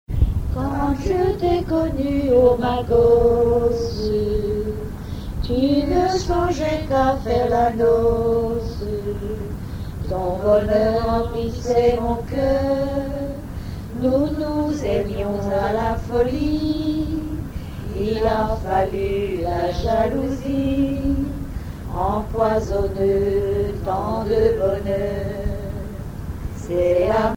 Genre strophique
Témoignages et chansons
Catégorie Pièce musicale inédite